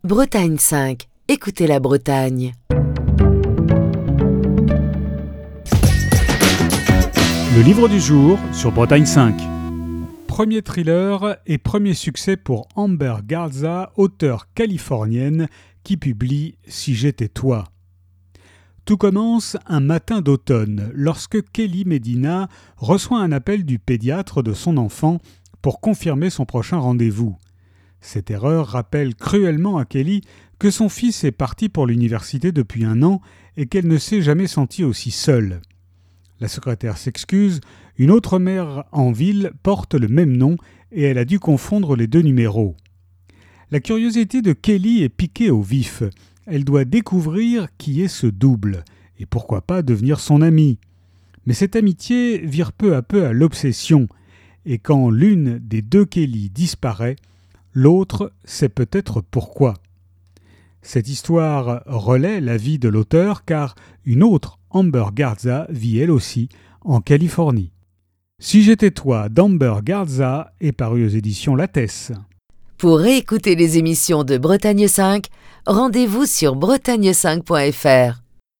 Chronique du 10 novembre 2021.